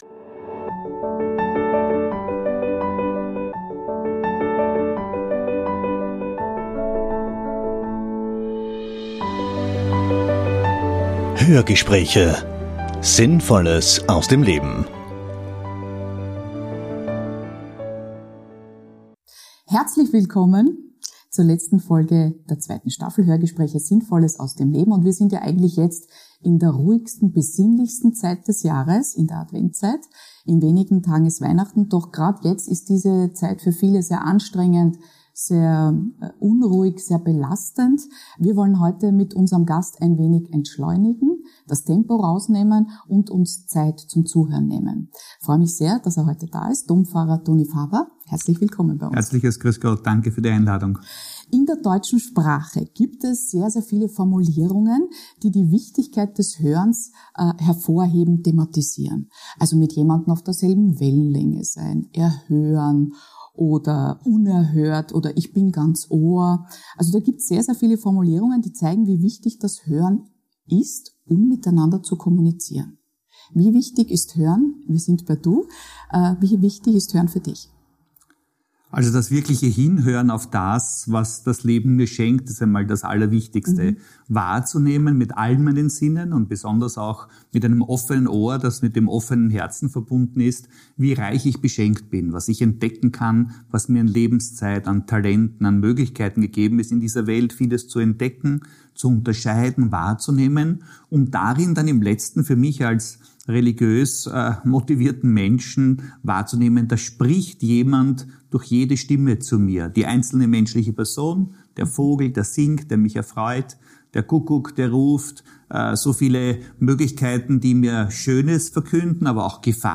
Wie er es schafft, dass genauso ihm Kirchgänger aufmerksam zuhören und seine Worte auch wirklich ankommen, erzählt Toni Faber im bewegenden Interview.